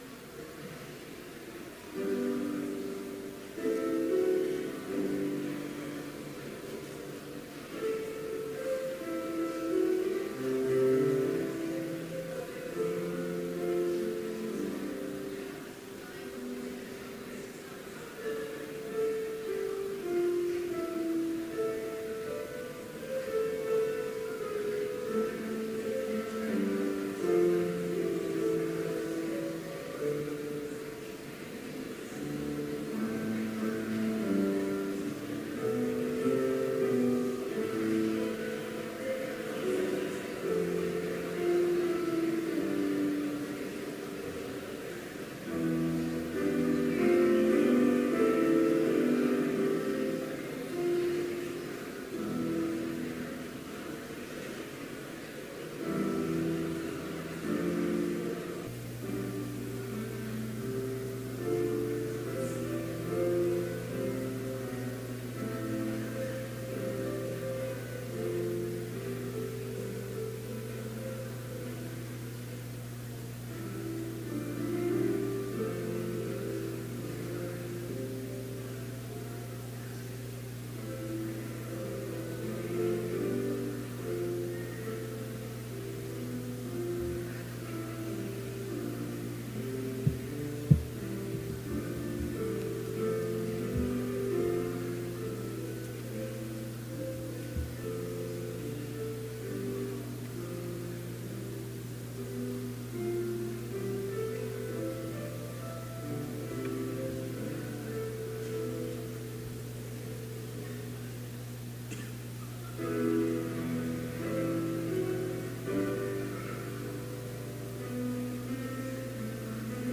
Complete service audio for Chapel - October 19, 2018